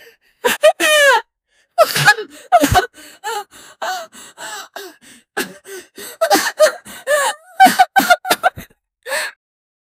woman being abused, she is getting hit, smacked, slapped and kicked, and shes crying 0:10 A angry female person talks and yells at a crying child mistreat him so hard say anything bad things at him and he cries like a poor little guy 0:47
woman-being-abused-she-is-ycphrseg.wav